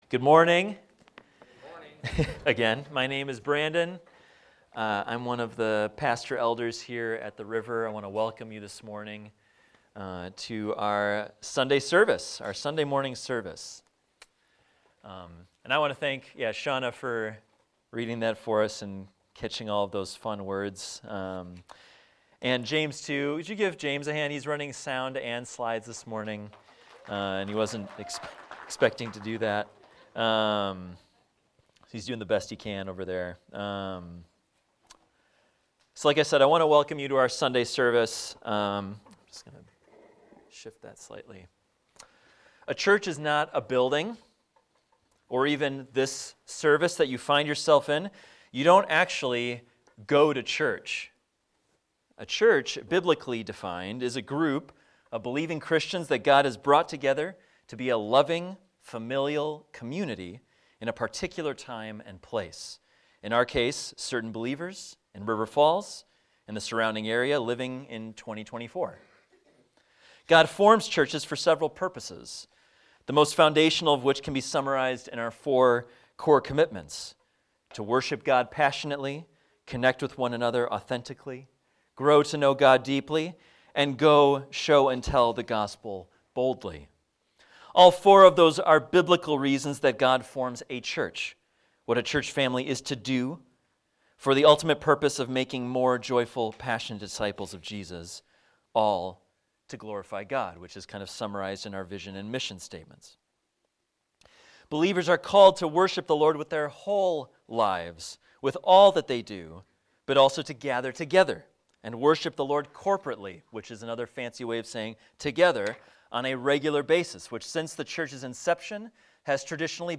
This is a recording of a sermon titled, "A Plot for the Future."